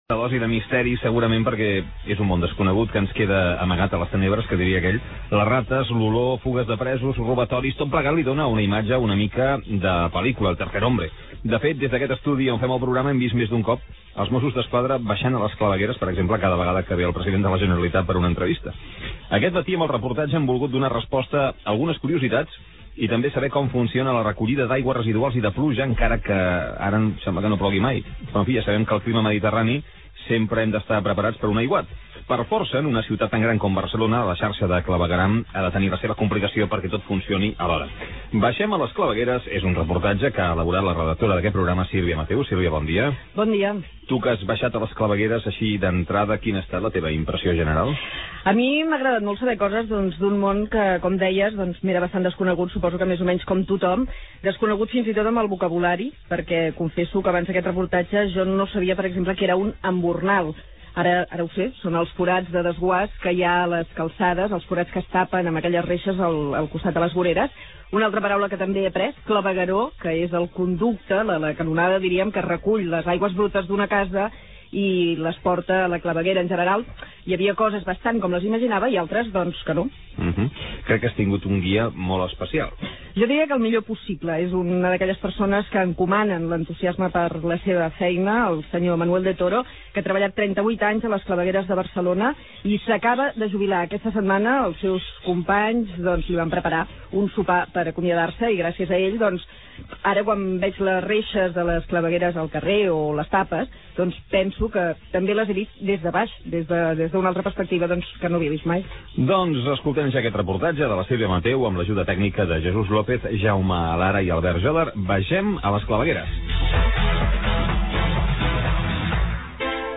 [ Maig 2005 ] El programa de “El Matí” de Catalunya Ràdio, que dirigeix i presenta Antoni Bassas, emet un reportatge sobre el clavegueram de la ciutat Catalunya Radio .